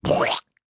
splat-v6.ogg